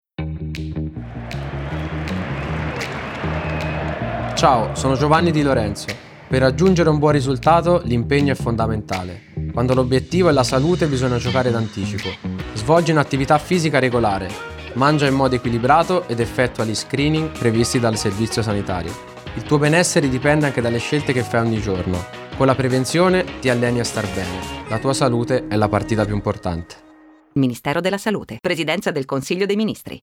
Lo spot radio
spot-salute_30s_radio_a_v4.mp3